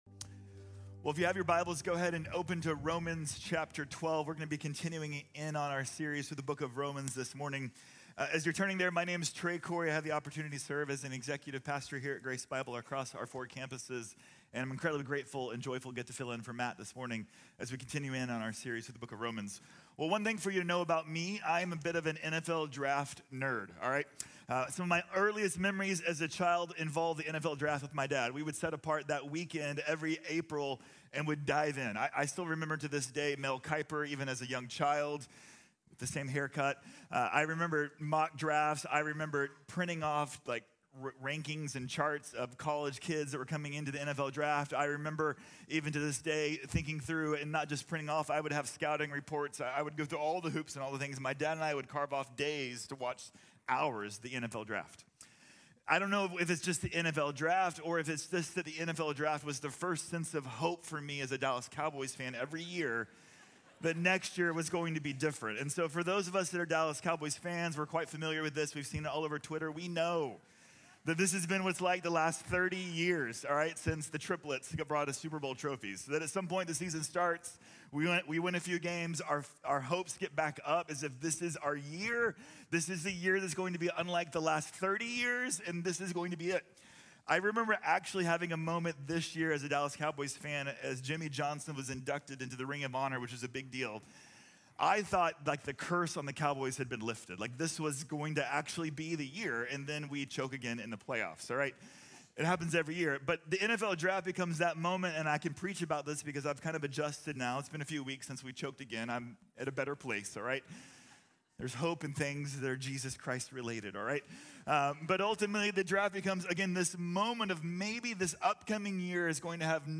Put Potential into Practice | Sermon | Grace Bible Church